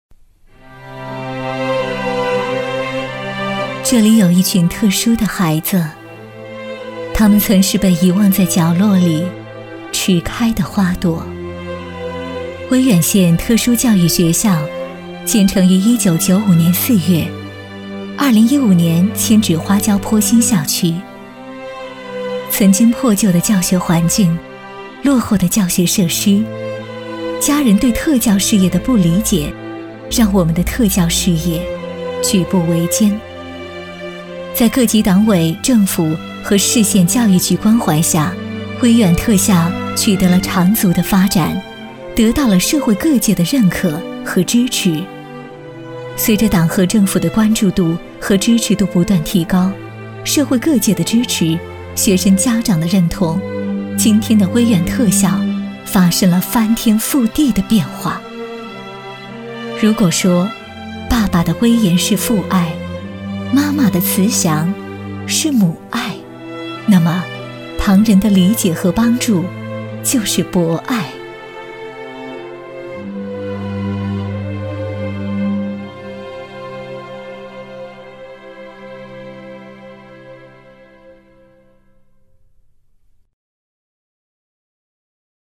女国66_专题_学校_威远特校专题片_亲切.mp3